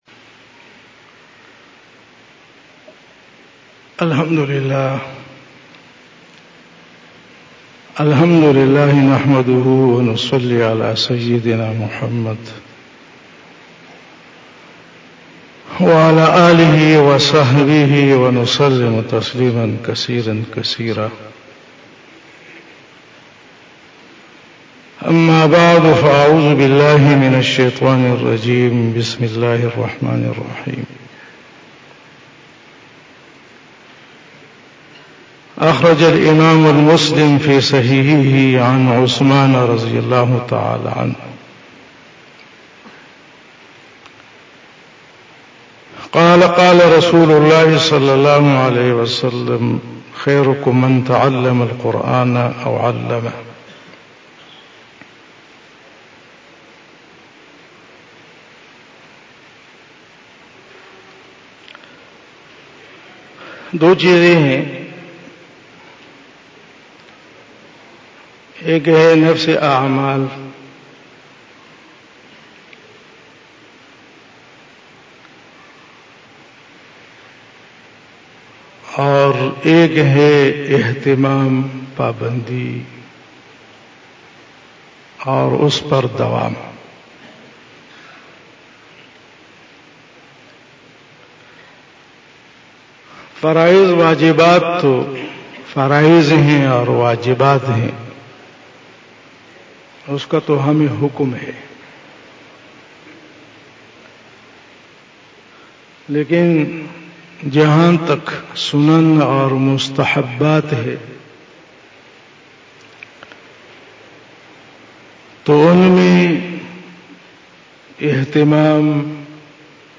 بیان شب جمعۃ المبارک